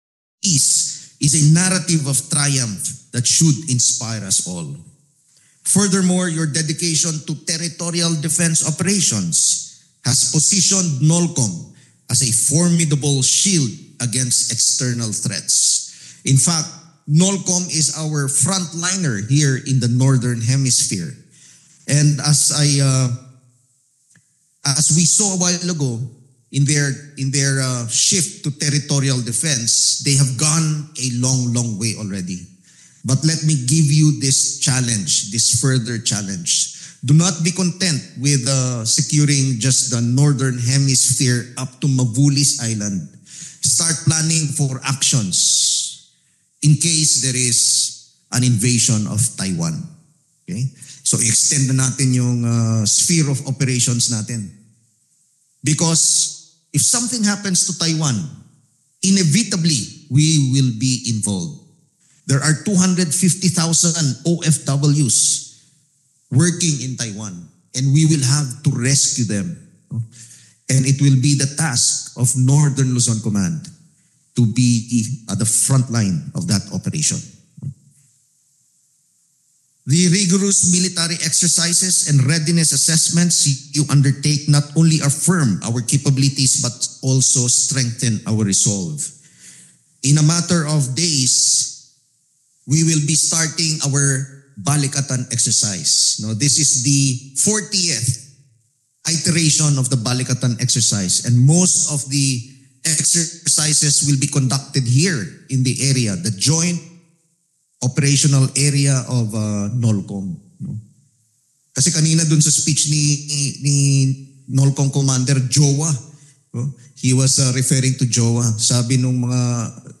General Romeo S. Brawner
"Peace Through Strength": Excerpted Remarks from Address at NOLCOM
delivered 1 April 2025, Camp Servillano Aquino, Tarlac City, Republic of the Philippines
Audio Note: AR-XE = American Rhetoric Extreme Enhancement